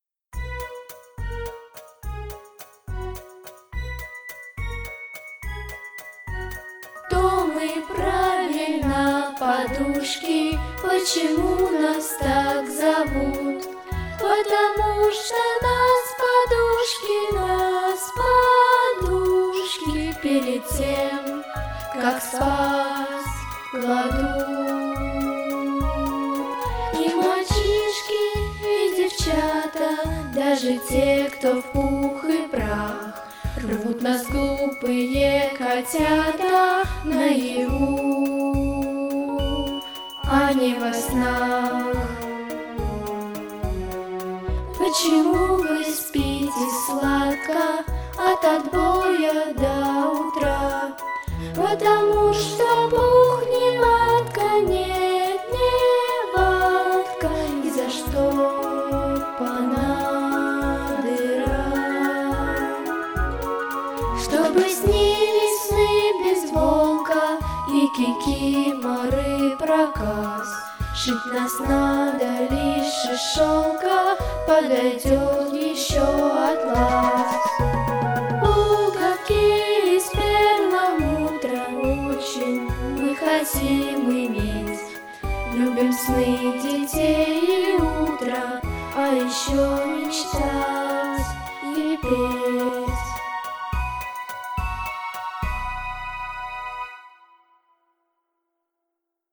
Записано в студии Easy Rider в ноябре–декабре 2025 года